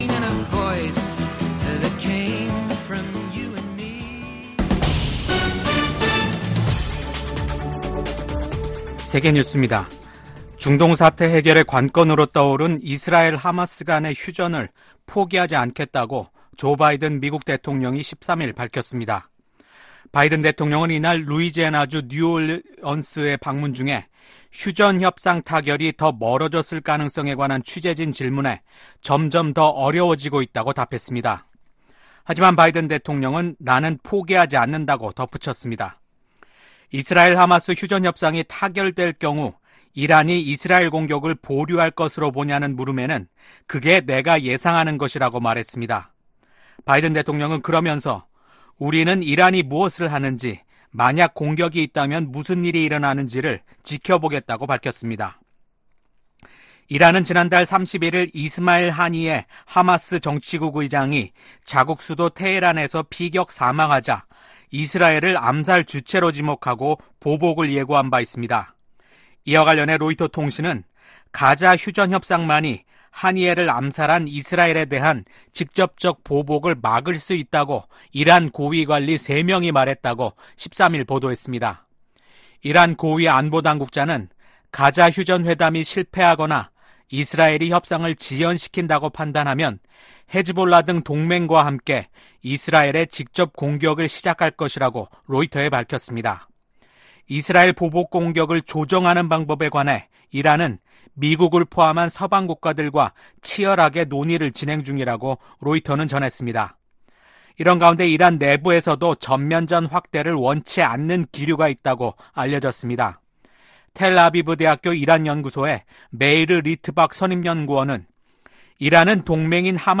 VOA 한국어 아침 뉴스 프로그램 '워싱턴 뉴스 광장' 2024년 8월 15일 방송입니다. 북한이 개성공단 내 철도 부속 건물 2개 동을 해체했습니다. 미 국무부는 북한이 러시아의 전쟁 수행을 지원하는 것을 좌시하지 않겠다고 밝혔습니다. 미국 국방부는 한국군의 전략사령부 창설 추진과 관련해 미한 동맹을 강조하며 긴밀하게 협력해 나갈 것이라고 밝혔습니다.